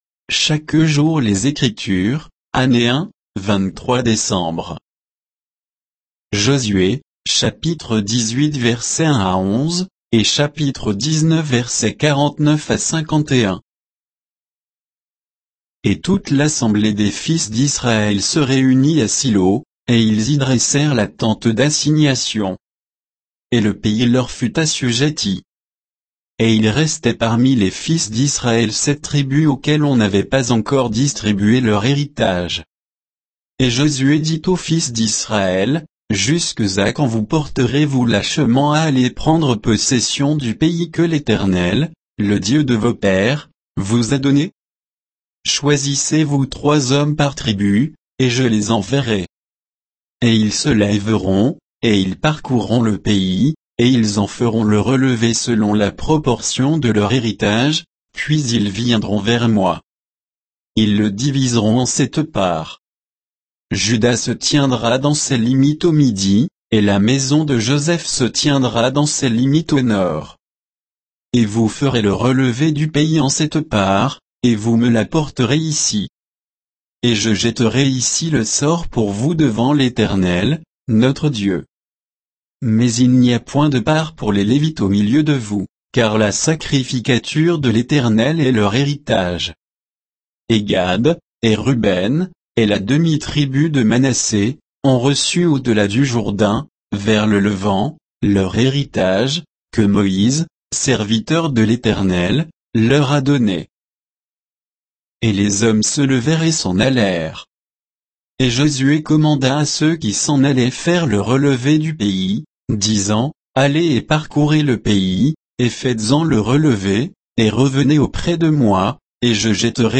Méditation quoditienne de Chaque jour les Écritures sur Josué 18, 1 à 11; 19, 49-51